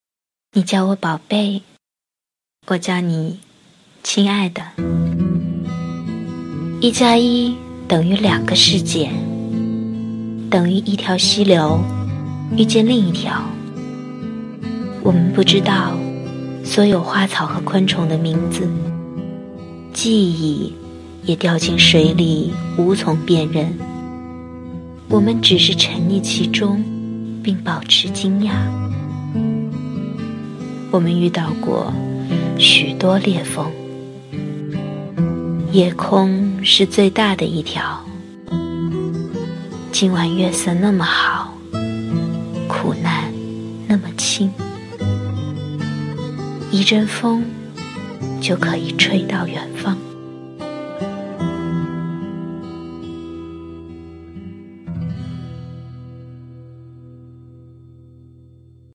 朗读